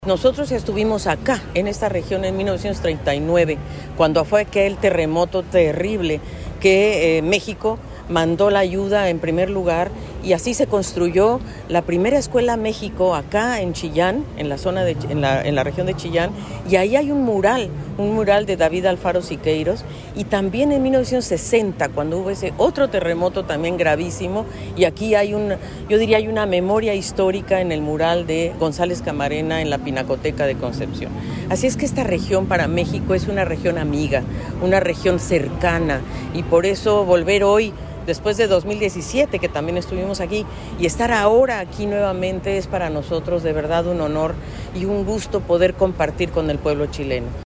La Plaza Independencia, en el centro de Concepción, fue el escenario de la ceremonia en la que autoridades regionales despidieron a los brigadistas mexicanos y españoles que, durante cuatro semanas, participaron del combate de los incendios forestales en la zona centro sur.